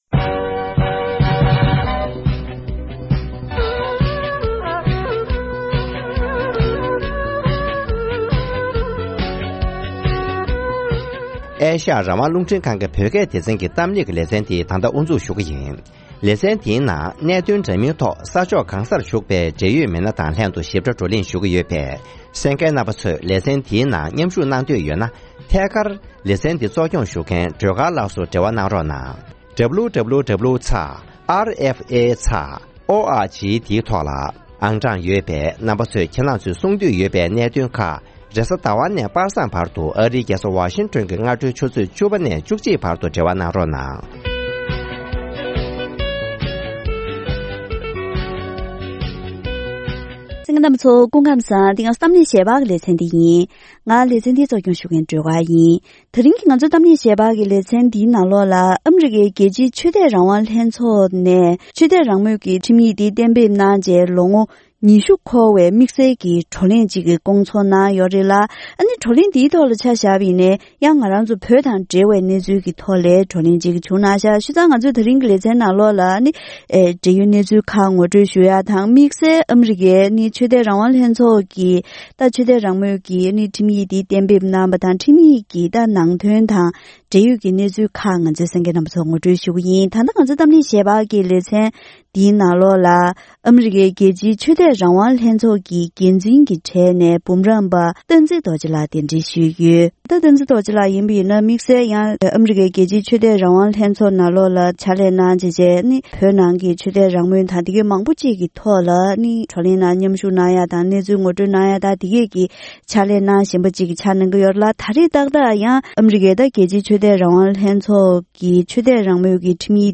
ཐེངས་འདིའི་གཏམ་གླེང་ཞལ་པར་གྱི་ལེ་ཚན་ནང་།